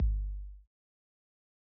Kicks
DB - Kick (24).wav